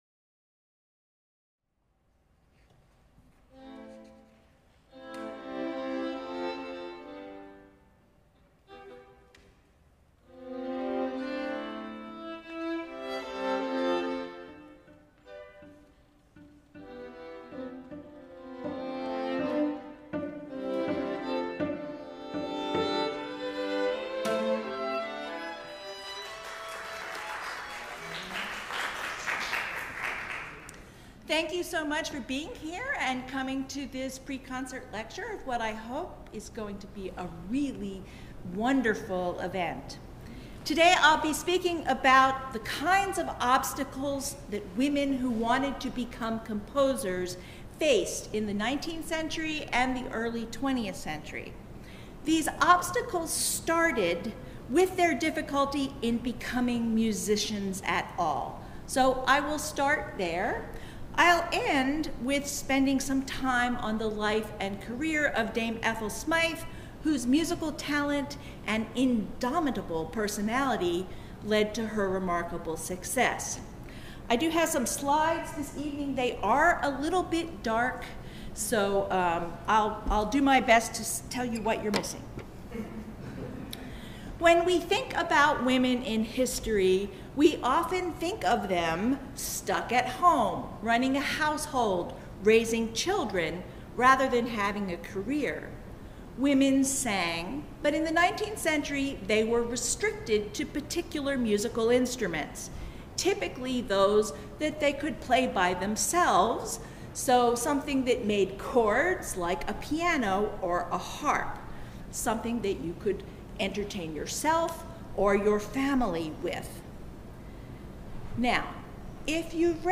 In this pre-concert event
gives a talk exploring the development of works by women artists and the barriers they faced.